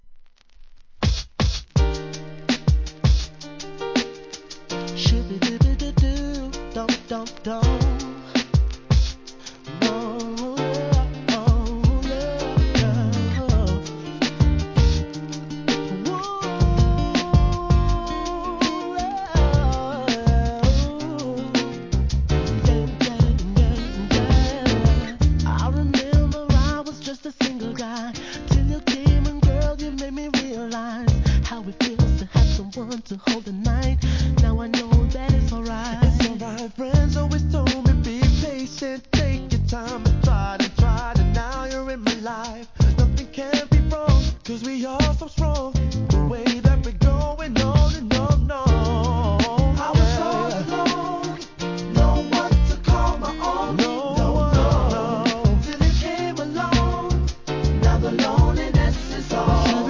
HIP HOP/R&B
硬派UKコーラス・グループによるアコースティックが美しい美メロナンバー！！